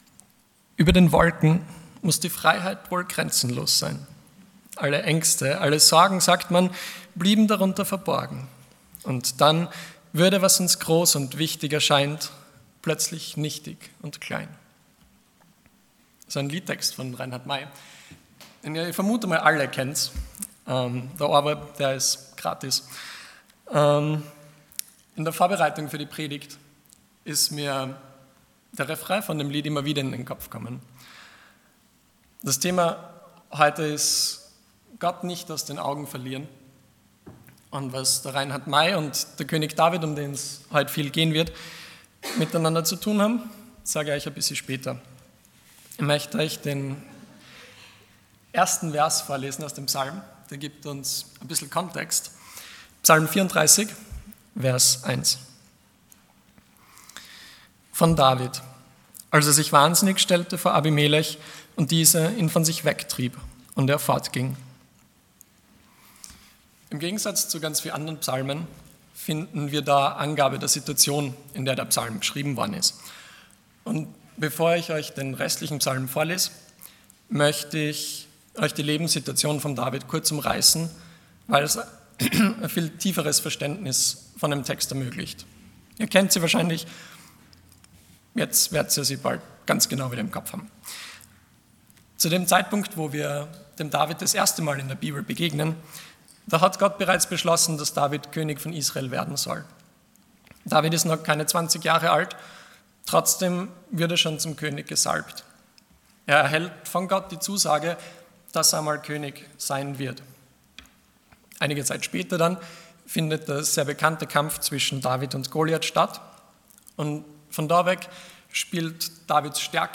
Passage: Psalm 34:1-23 Dienstart: Sonntag Morgen